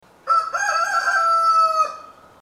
Kohout
Každé ráno na dvorku budí další zvířecí kamarády hlasitým: „Kikirikí!“
kohout.mp3